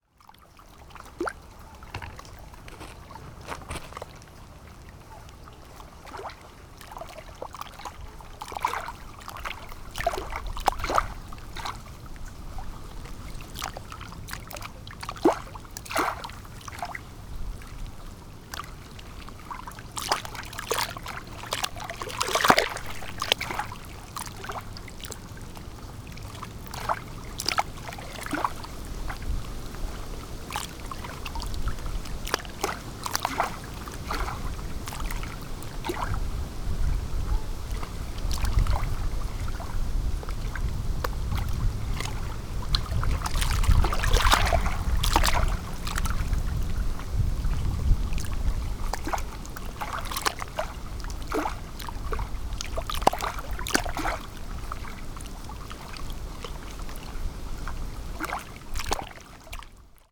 On avait dit aux amis que l’on arriverait dans l’après-midi et il était un peu tôt ; alors on avait marché un peu au bord du lac et j’en avais profité pour enregistrer les vaguelettes qui tapaient contre la berge.
Aveyron, août 2024